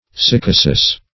Search Result for " sycosis" : The Collaborative International Dictionary of English v.0.48: Sycosis \Sy*co"sis\, n. [NL., fr. Gr.